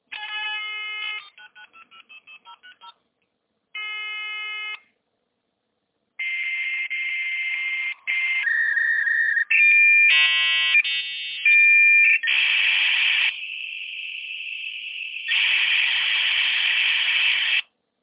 modem.mp3